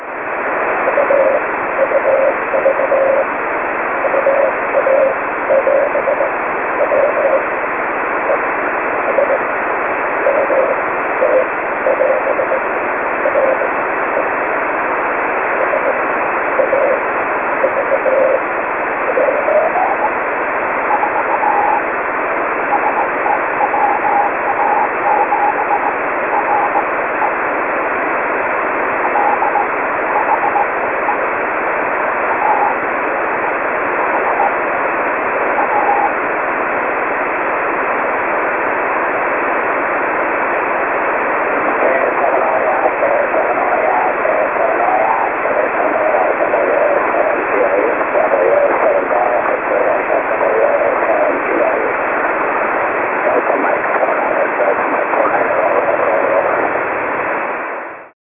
While it never became a "pure" CW note, it could vary from a broad hiss to an airy note.
While badly distorted, the audio was copiable:
The signal, had the path been free of multipath, would have been pretty easy copy - but even as it was, almost every word during the SSB portion is copiable (if you are used to copying signals like this, anyway...) and the CW portion is easily 100% copiable. (Note that I retuned slightly during the CW portion, and yes, I know that the SSB portion is off frequency a bit, but I chose not to retune during that part.)